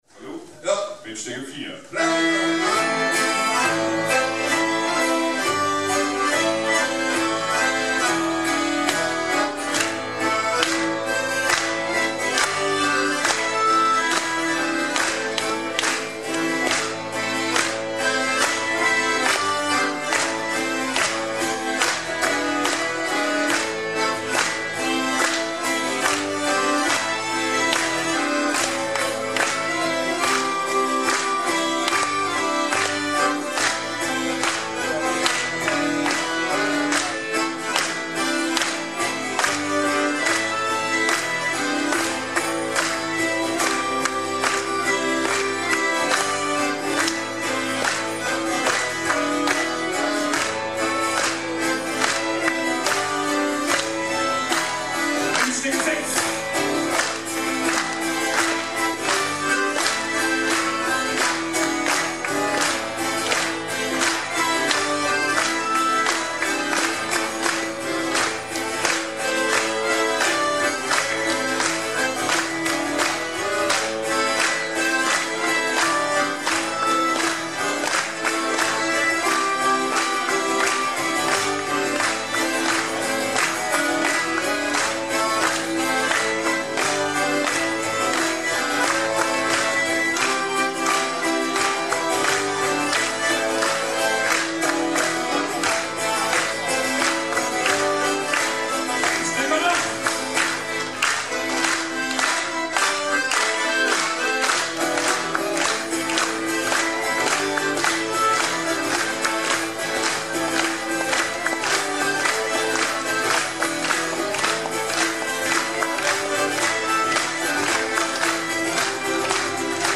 Live in Dunum